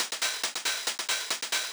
K-4 Hats.wav